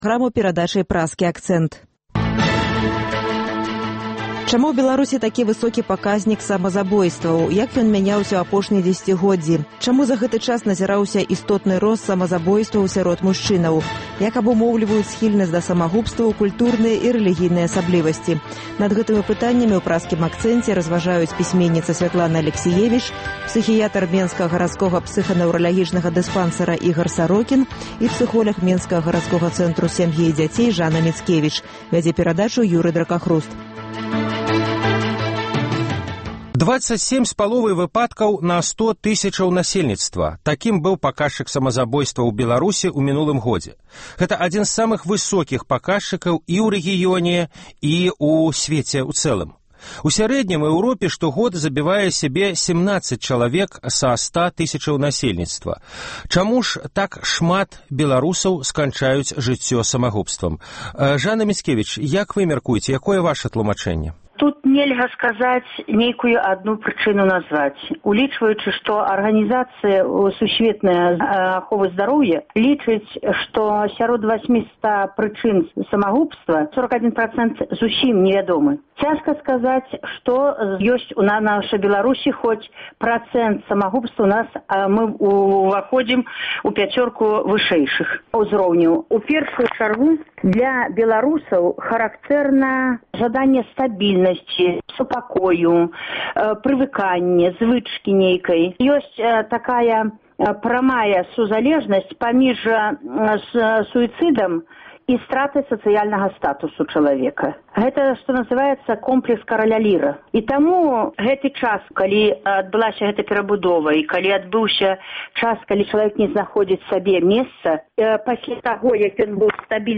Чаму за гэты час назіраўся істотны рост самазабойстваў сярод мужчын? Як абумоўліваюць схільнасьць да самазабойстваў культурныя і рэлігійныя асаблівасьці? Над гэтымі пытаньнямі ў “Праскім акцэнце” разважаюць пісьменьніца Сьвятлана Алексіевіч